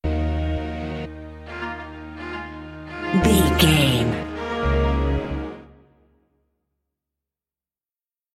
Epic / Action
Aeolian/Minor
E♭
drum machine
synthesiser
funky
aggressive
hard hitting